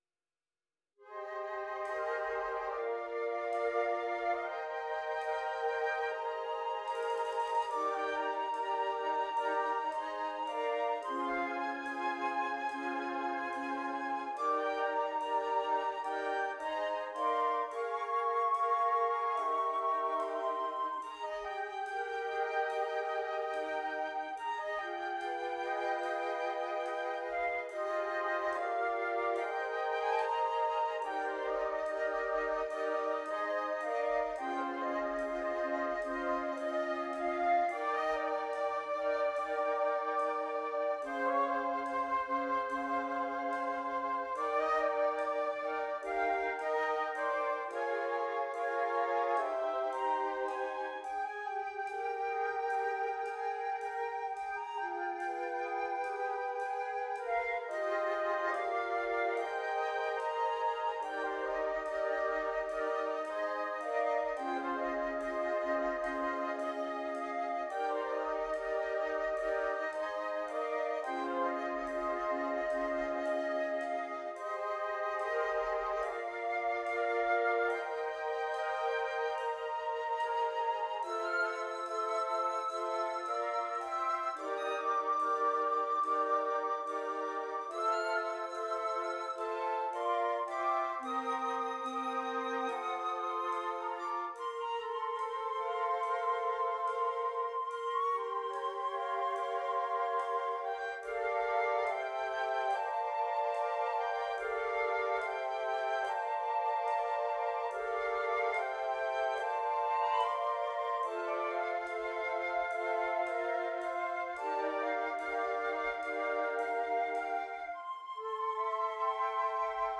5 Flutes